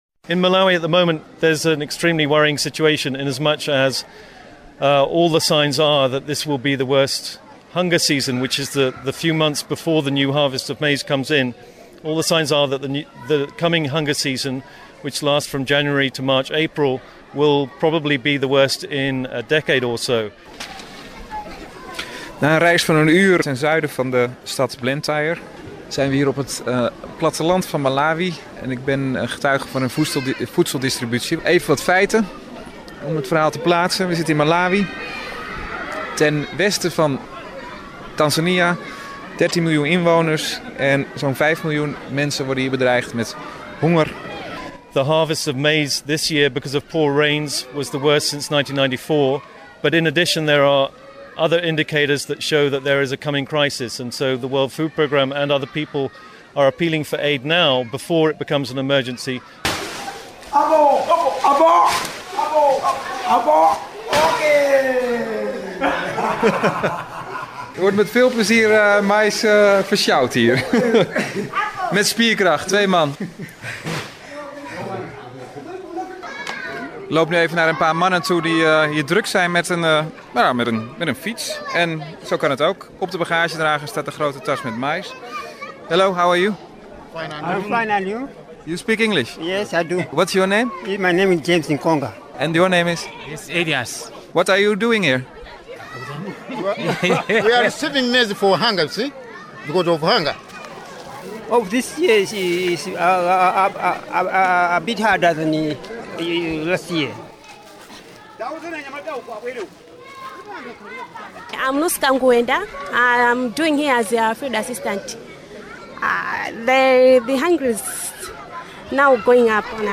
audio report here (Windows Media).